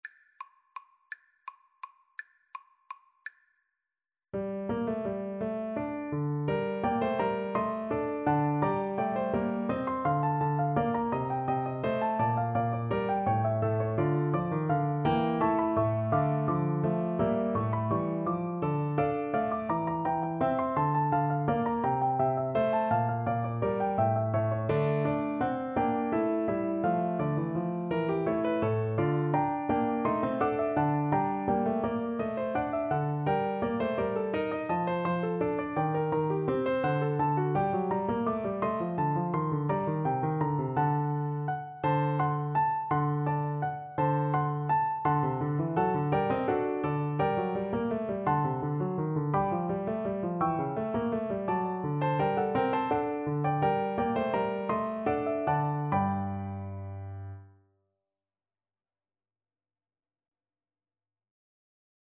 3/8 (View more 3/8 Music)
Classical (View more Classical Clarinet Music)